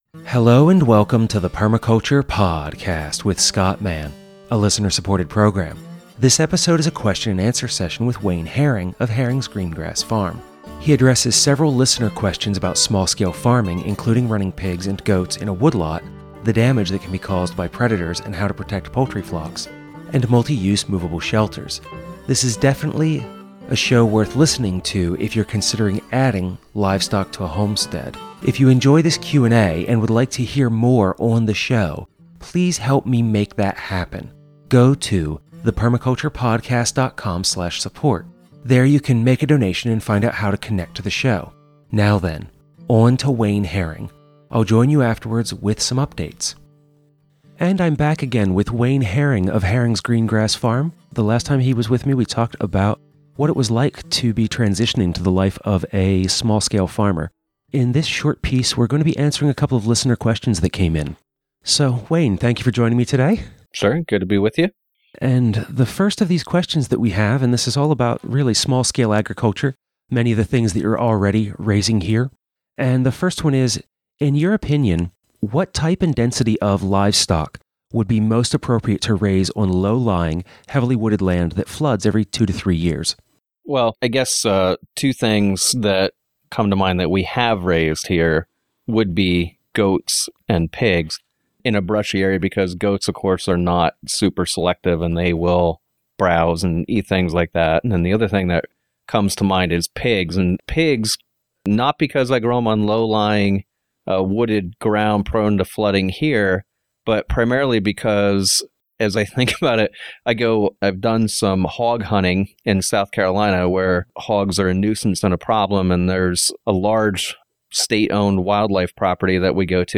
Ever since then I've wanted to interview him because he's doing the work of starting a stewardship farm from the ground up in the best way he can.